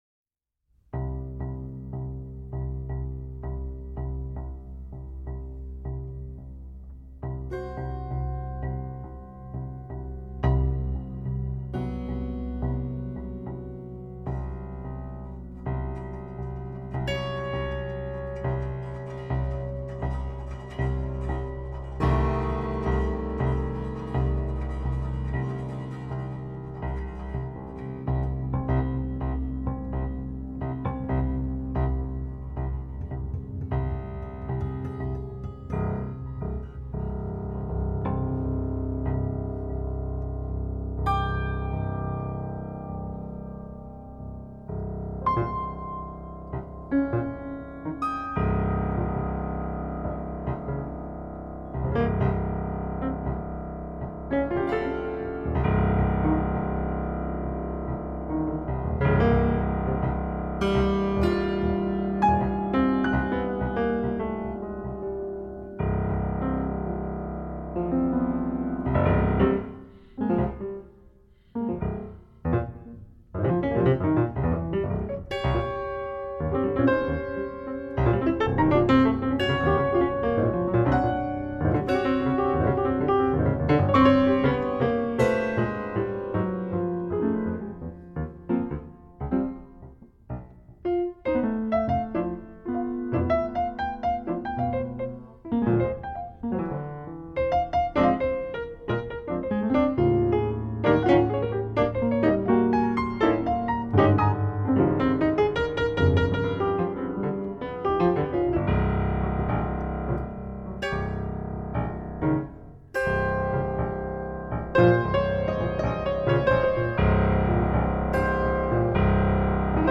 極上のピアノ作品です！